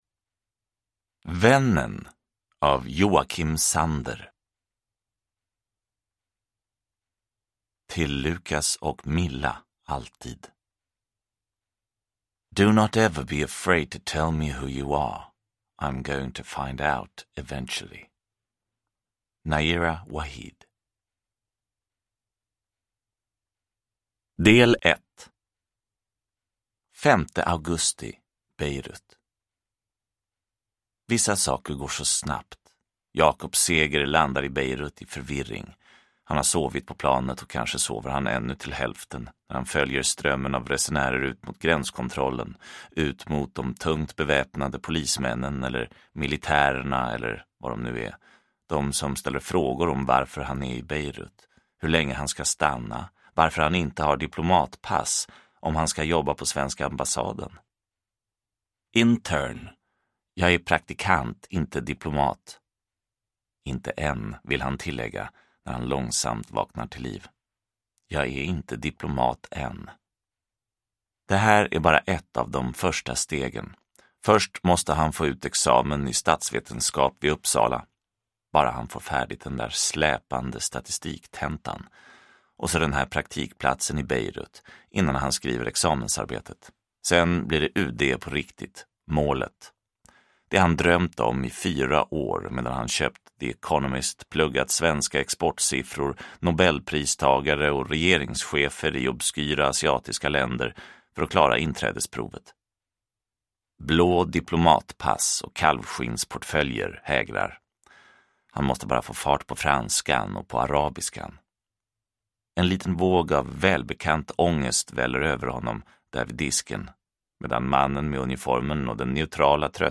Vännen – Ljudbok – Laddas ner